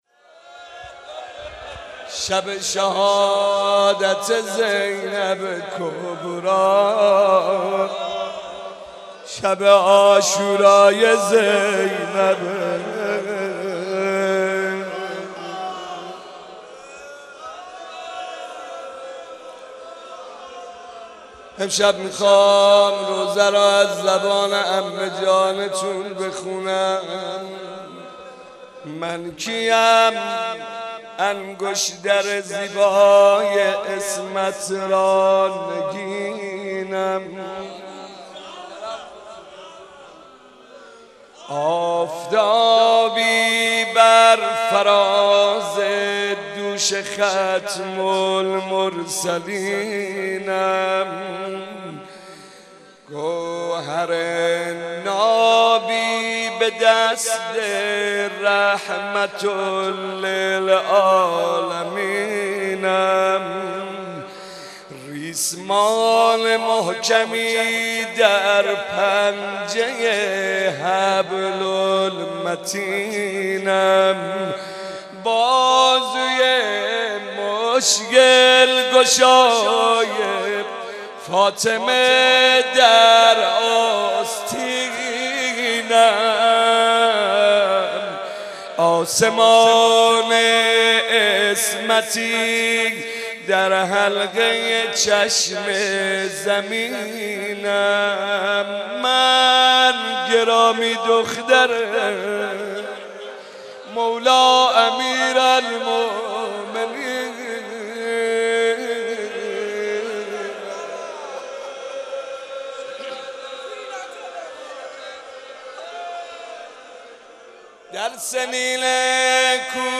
مناسبت : وفات حضرت زینب سلام‌الله‌علیها
قالب : روضه